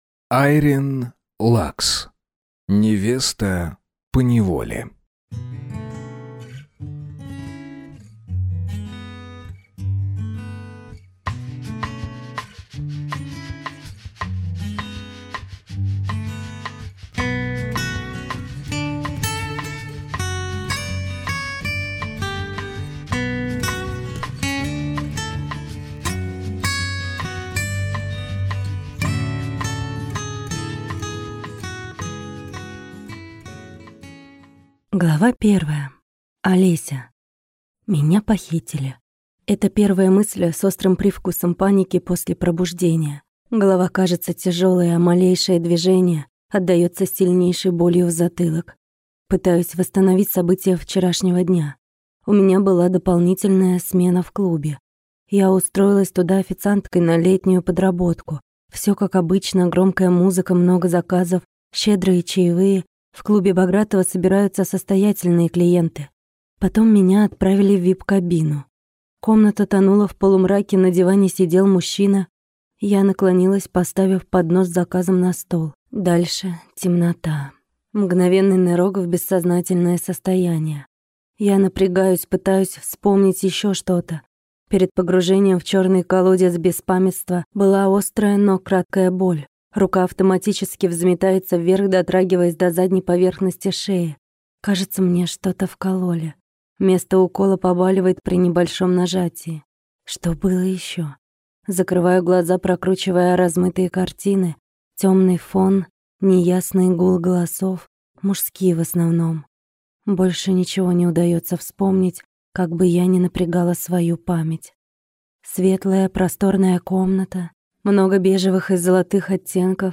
Аудиокнига Невеста поневоле | Библиотека аудиокниг